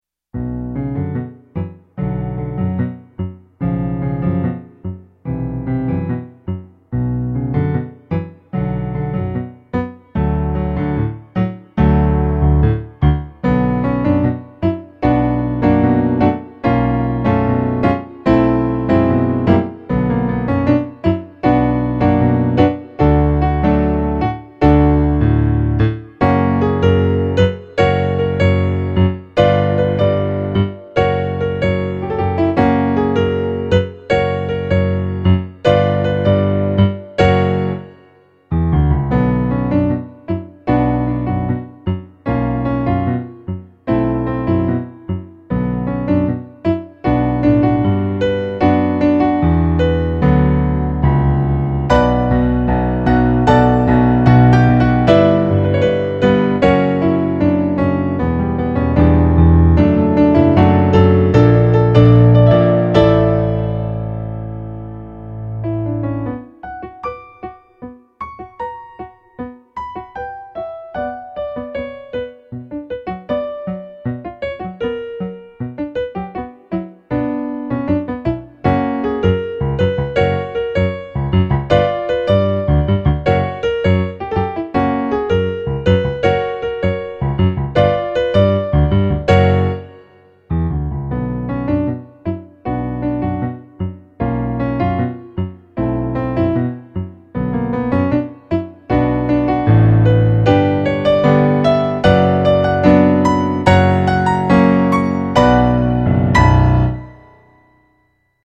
eight piano solo arrangements.  34 pages.
tango remix